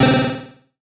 Synth Stab 05 (C).wav